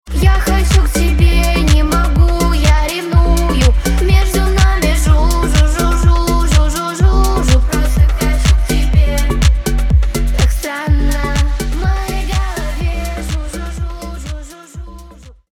поп
цикличные